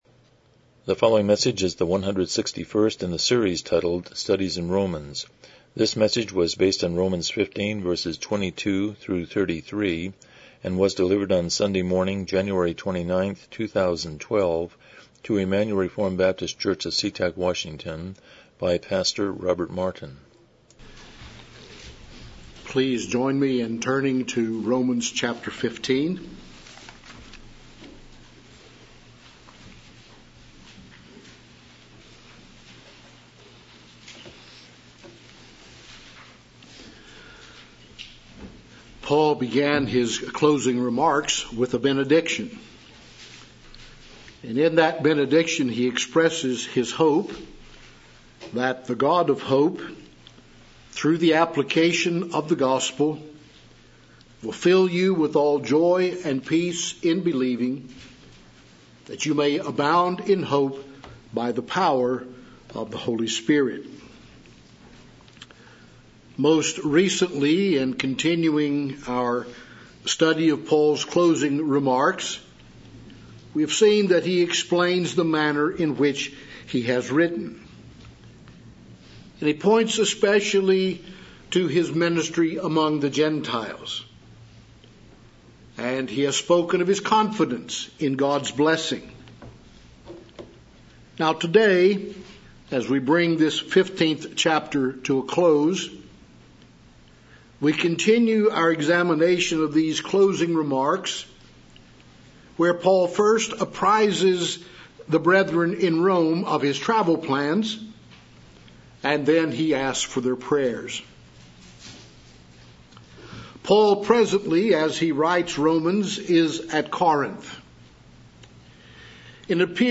Romans 15:22-33 Service Type: Morning Worship « 139 Chapter 29.1